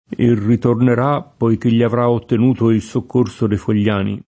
poiché [poik%+] (meno com. poi che [id. o p0i ke+]) cong.